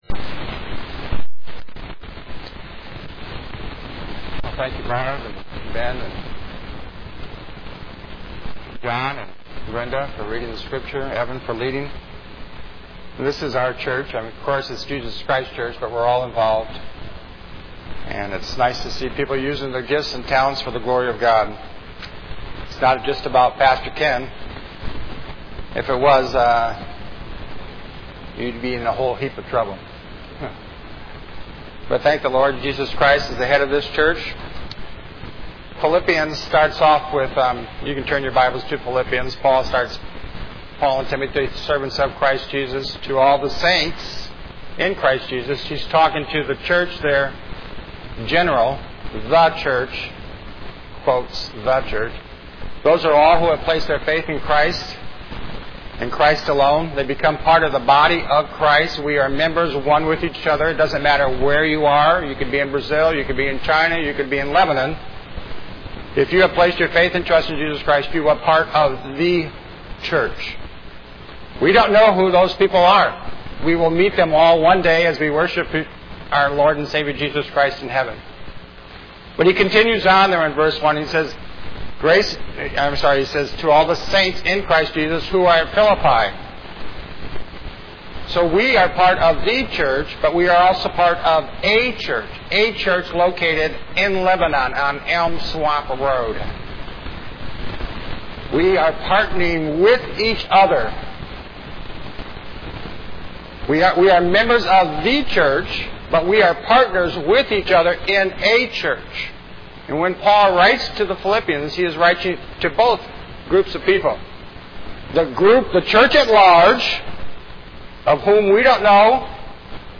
5.11.14_sermon.mp3